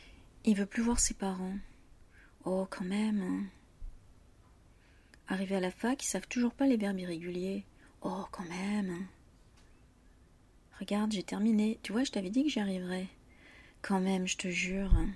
l’incrédulité : cela ne paraît pas possible.